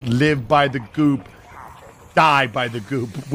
live by the goop Meme Sound Effect
This sound is perfect for adding humor, surprise, or dramatic timing to your content.